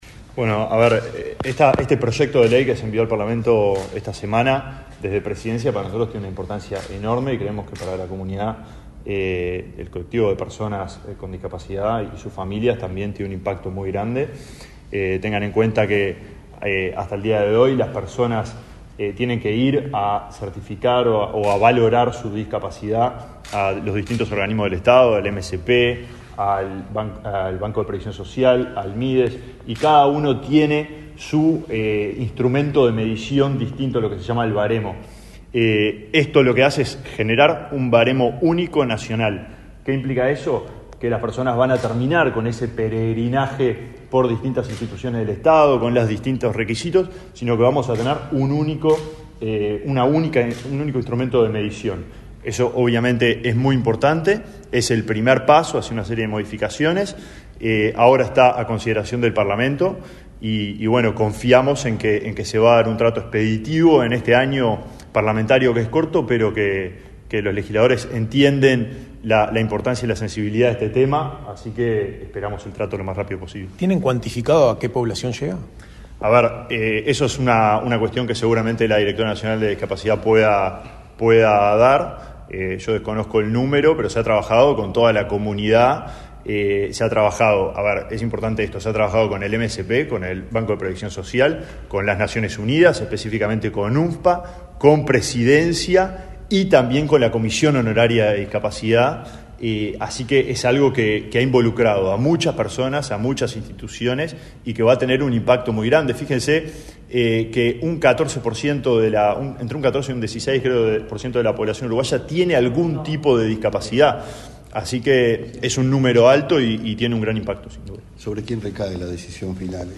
Declaraciones a la prensa del ministro del Mides, Alejandro Sciarra
Declaraciones a la prensa del ministro del Mides, Alejandro Sciarra 31/05/2024 Compartir Facebook X Copiar enlace WhatsApp LinkedIn El Gobierno envió al Parlamento un proyecto de ley para crear un baremo único nacional de discapacidad. Este 31 de mayo, el Ministerio de Desarrollo Social (Mides) realizó una conferencia de prensa para informar acerca de la iniciativa. En la oportunidad, el titular de la cartera, Alejandro Sciarra, realizó declaraciones.